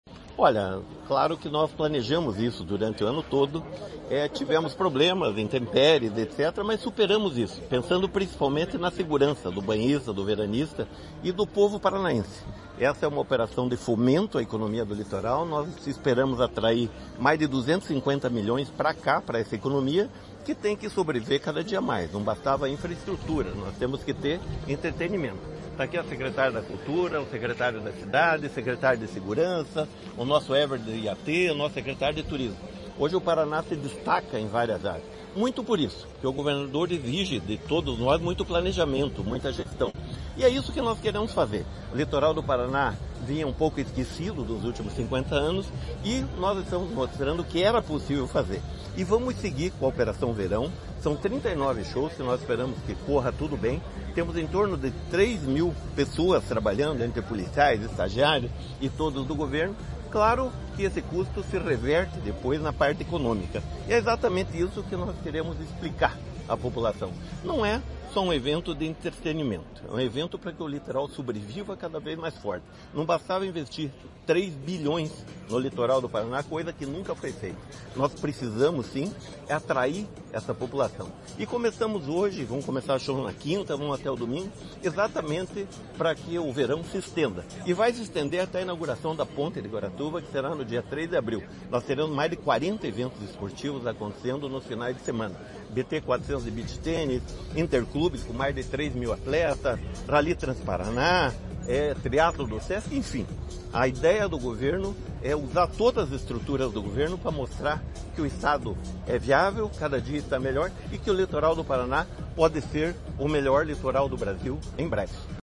Sonora do secretário do Esporte, Hélio Wirbiski, sobre o primeiro fim de semana de shows do Verão Maior Paraná